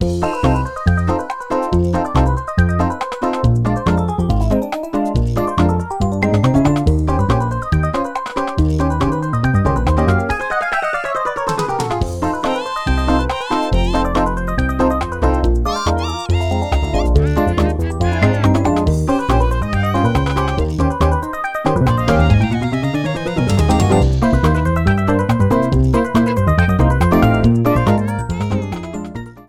applied fade-out to last two seconds
Fair use music sample